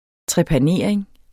Udtale [ tʁεpaˈneˀɐ̯eŋ ]